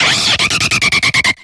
Grito de Noivern.ogg
Grito_de_Noivern.ogg.mp3